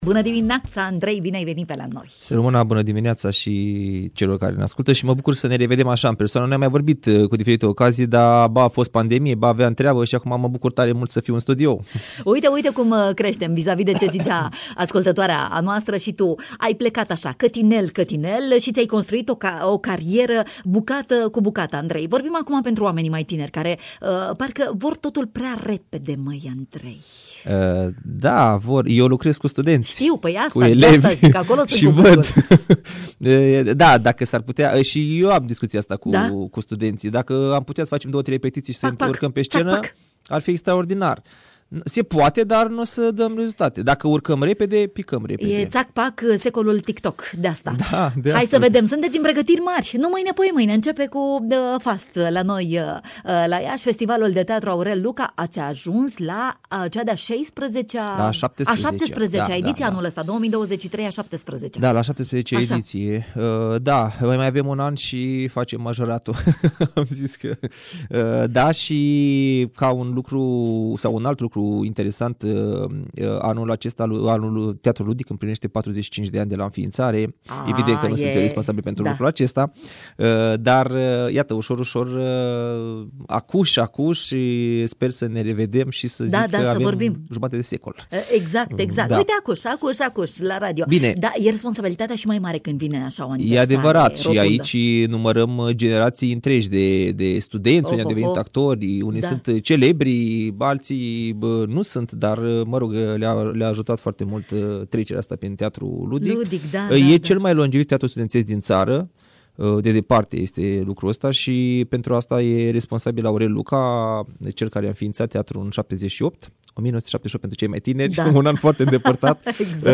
ne-a adus detalii în matinal Radio Iași